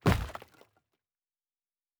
Stone 09.wav